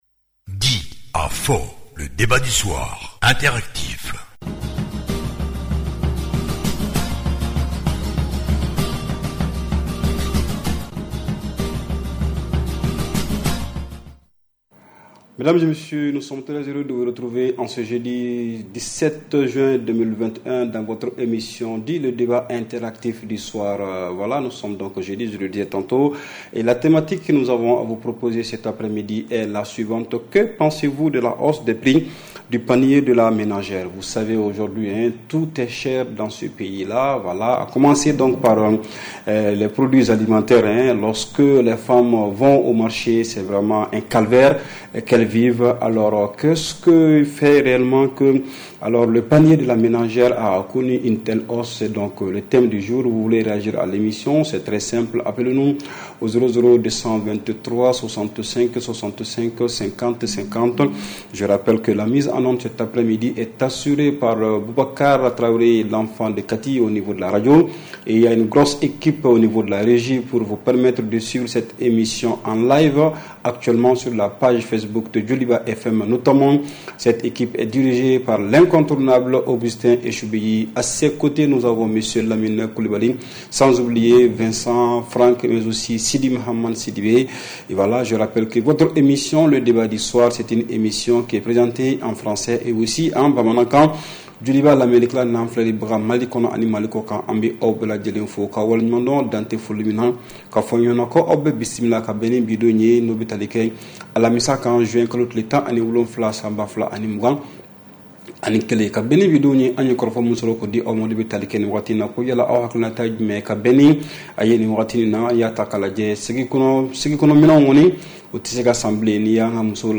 REPLAY 17/06 – « DIS ! » Le Débat Interactif du Soir